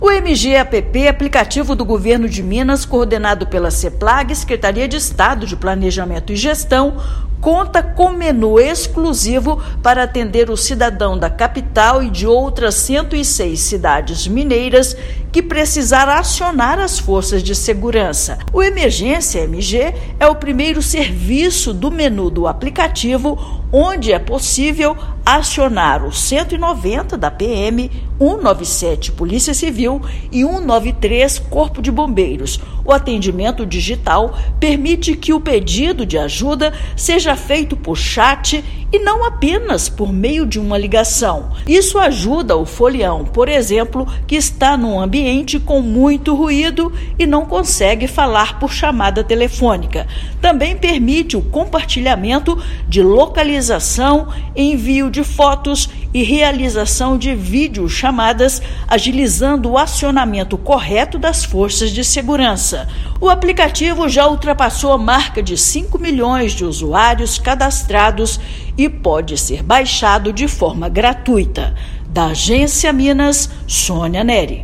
Serviço pode ajudar folião a solicitar ajuda das polícias, acionar o Corpo de Bombeiros e conversar com as forças de segurança de forma rápida por chat. Ouça matéria de rádio.